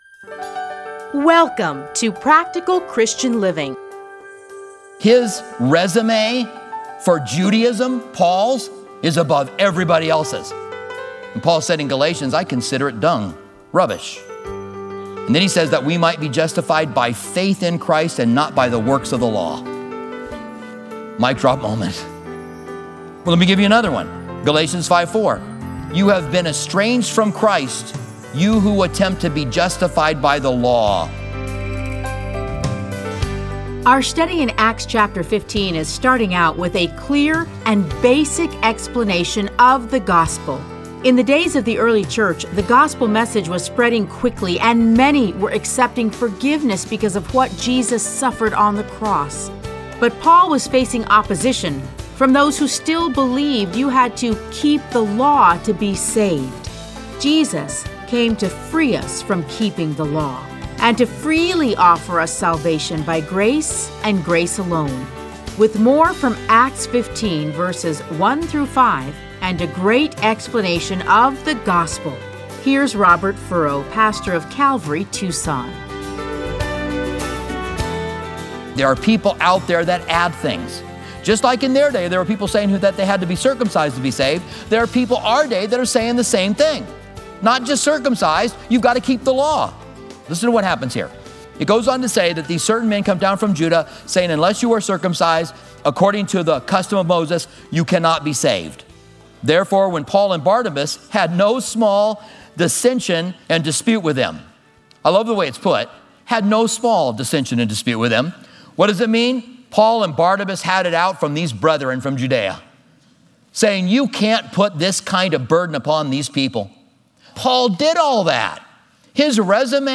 Listen to a teaching from Acts 15:1-5.